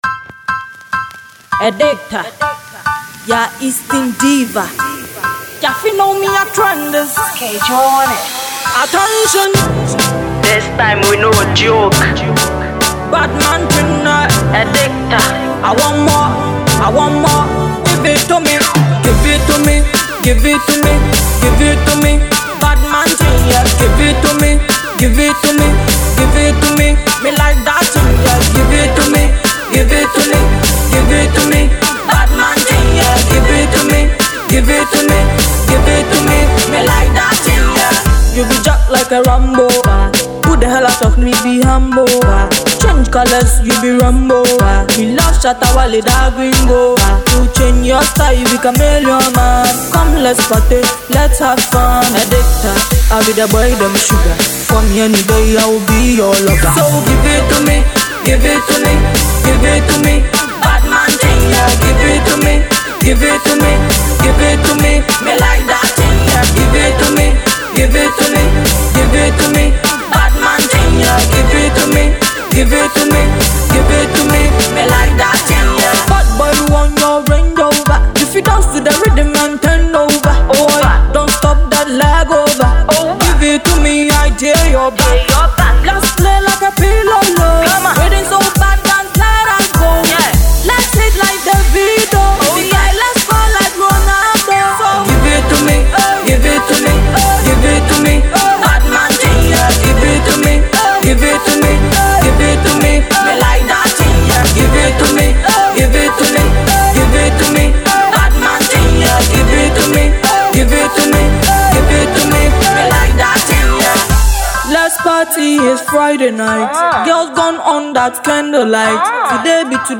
dancehall songstress
Afro Dancehall music